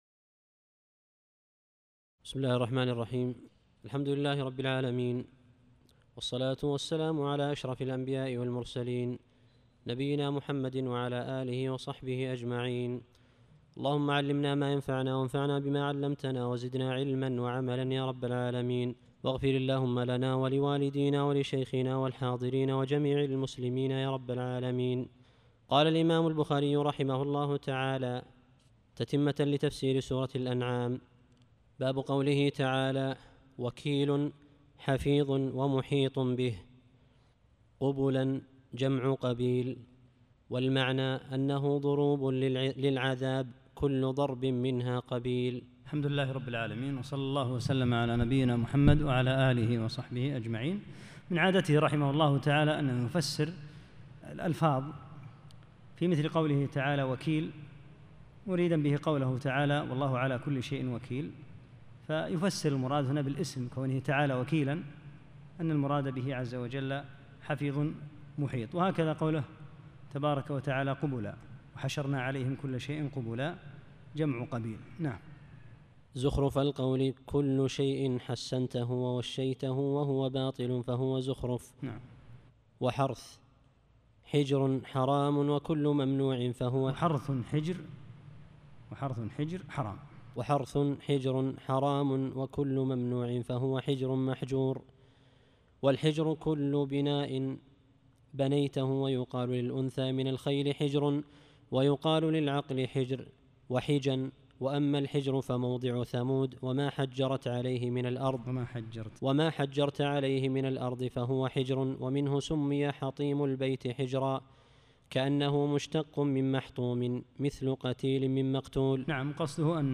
13- الدرس الثالث عشر